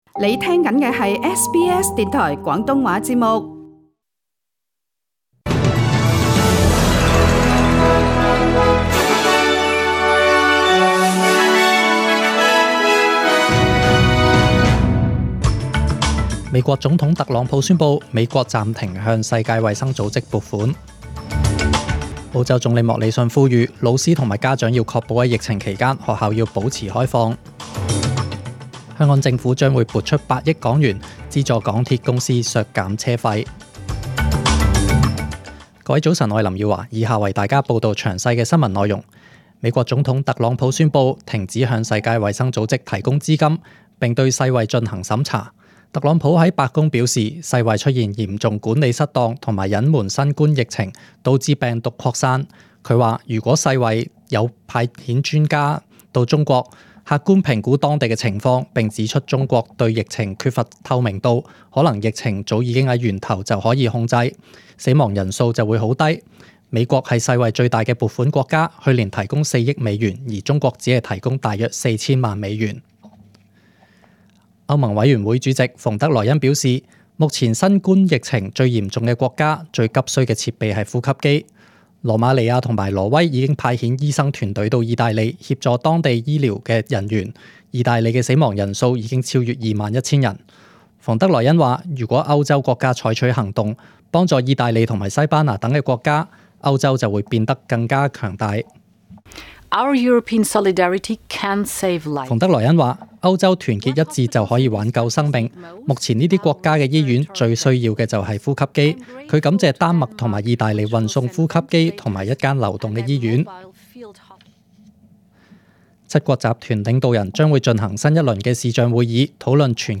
SBS中文新聞 （四月十五日）